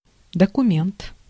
Ääntäminen
IPA : /ˈdɒkjʊmənt/